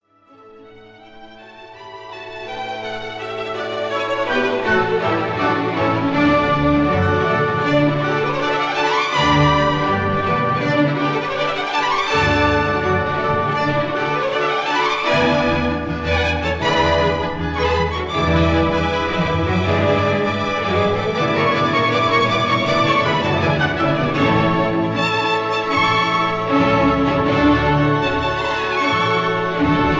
Recorded in Abbey Road Studio No. 1, London